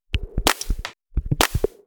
Index of /VEE/VEE Electro Loops 128 BPM
VEE Electro Loop 058.wav